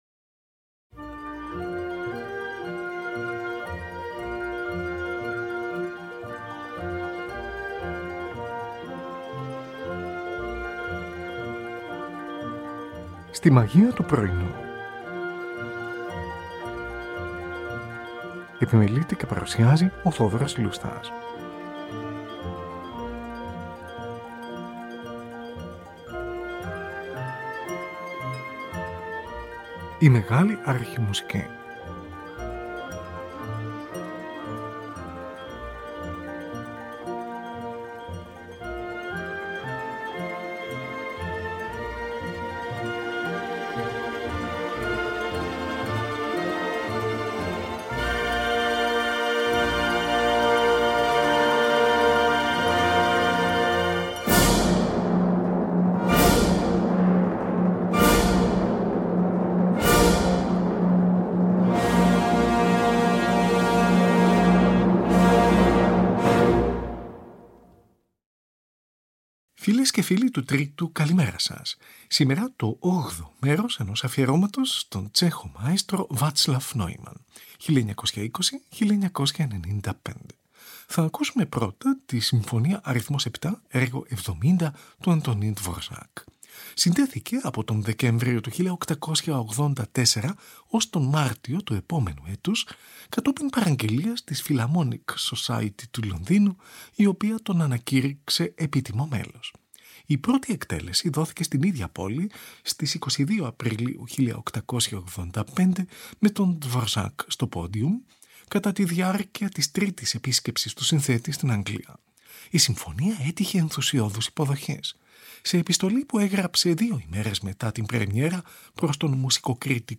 Antonín Dvořák: ορχηστρική εισαγωγή «Οθέλλος», έργο 93.